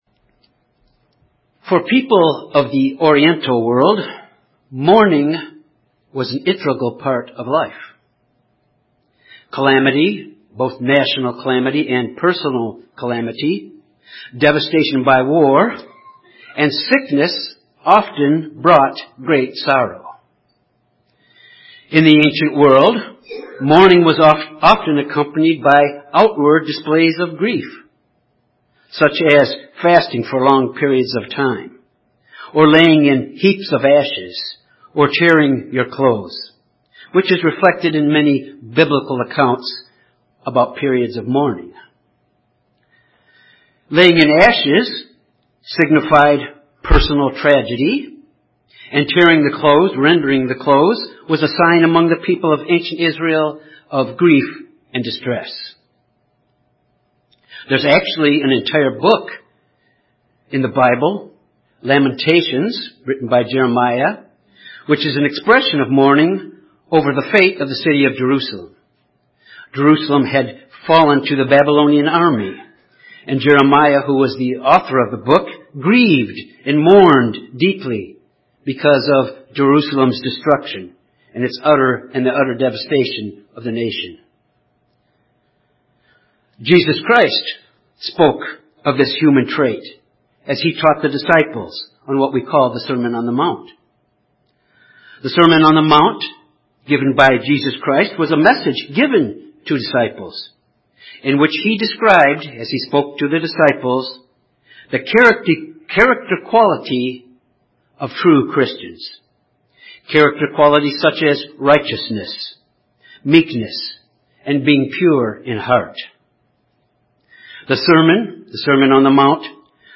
This sermon examines three aspects of the quality of mourning as it applies to Christ’s disciples.